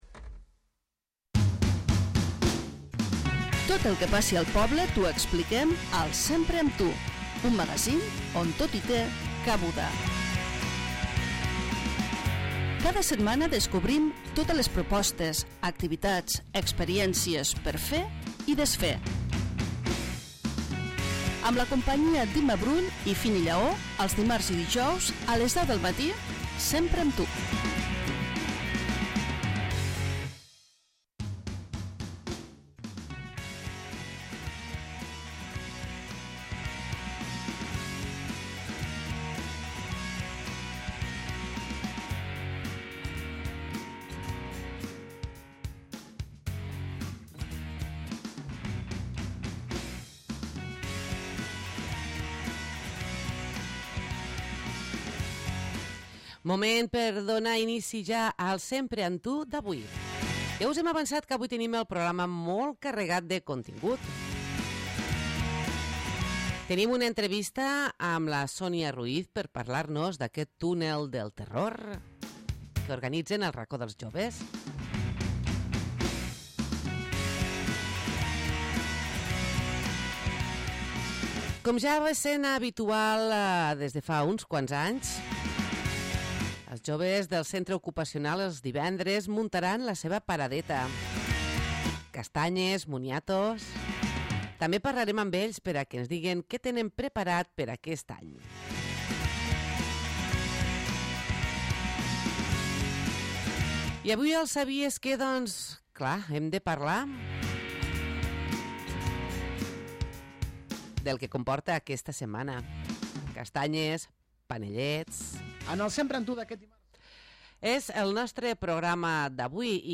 Nova edició del magazine de La Cala Ràdio on coneixem les propostes per celebrar la Castanyada aquest cap de setmana.